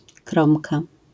wymowa:
IPA[ˈkrɔ̃mka], AS[krõmka], zjawiska fonetyczne: nazal.